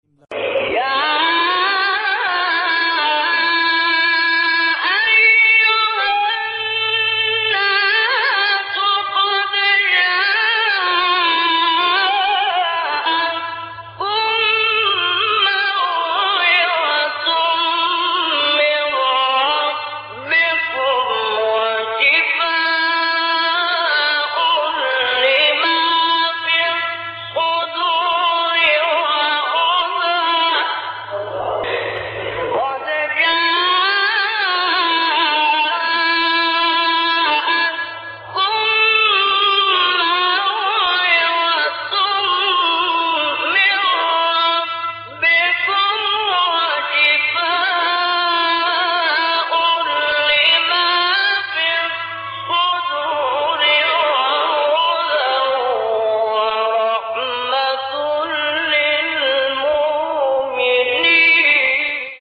مقام : نهاوند